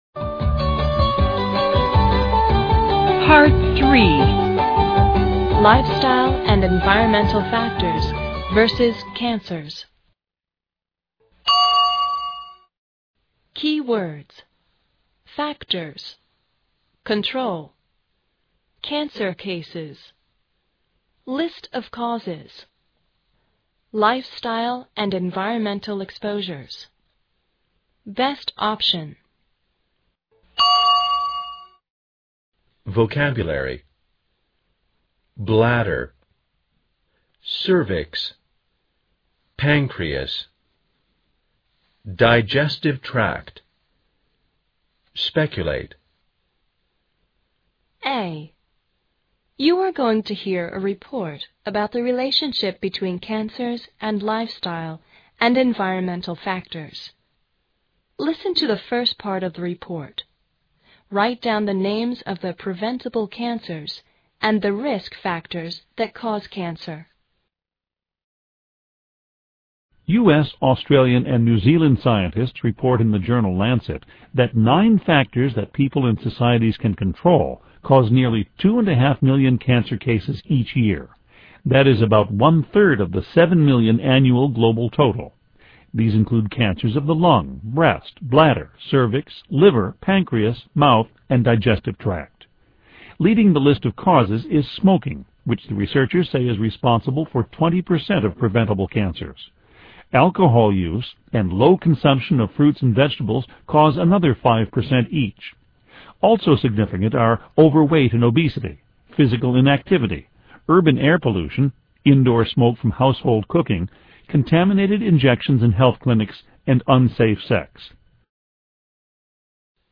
A. You're going to hear a report about the relationship between cancers and lifestyle & environmental factors.